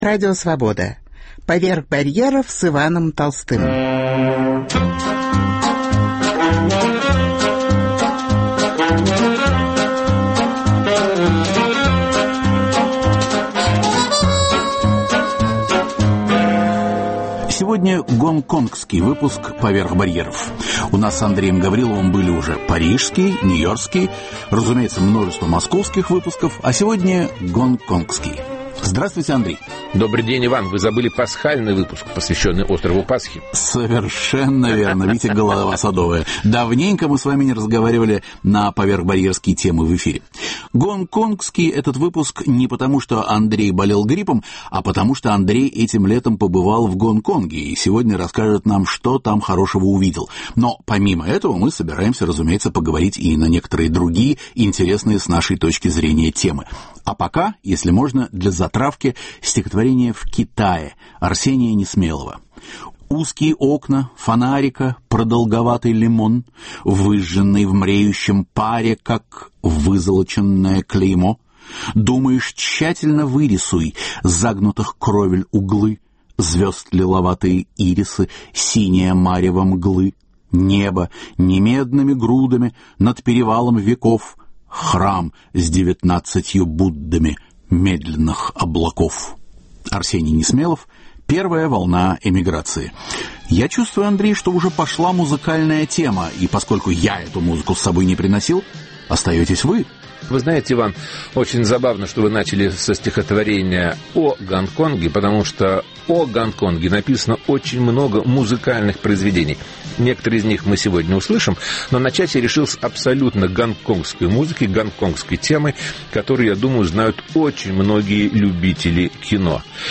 Звучит гонконгская музыка.